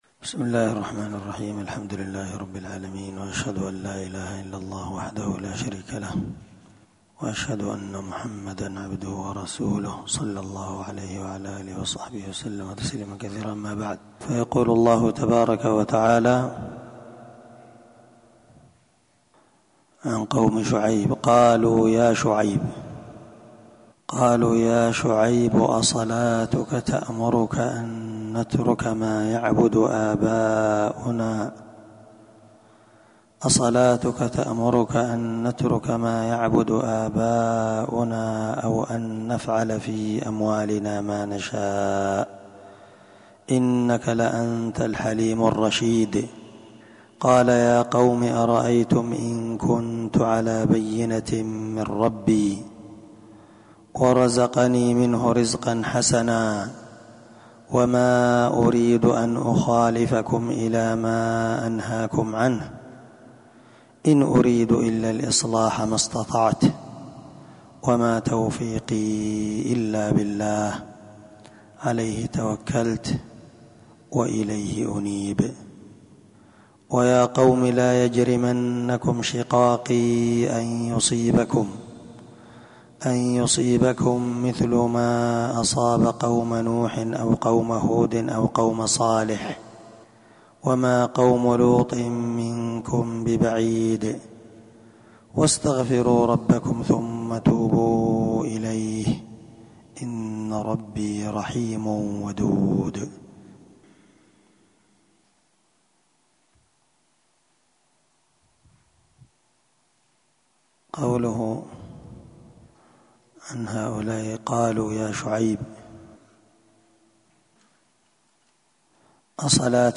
641الدرس 22تفسير آية ( 87- 90) من سورة هود من تفسير القرآن الكريم مع قراءة لتفسير السعدي
دار الحديث- المَحاوِلة- الصبيحة.